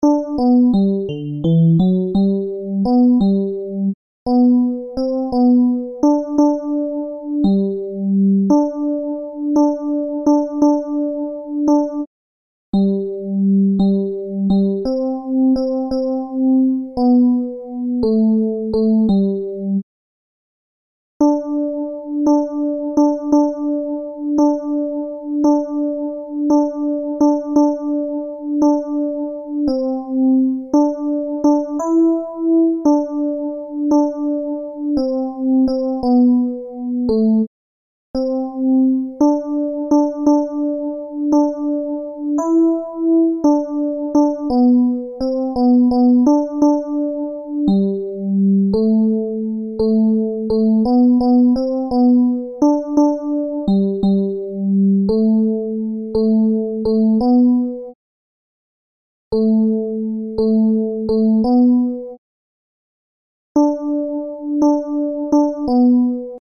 Ténors
giovani_liete_tenors.MP3